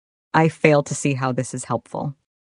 Added sound notification for login failure
fail2c.ogg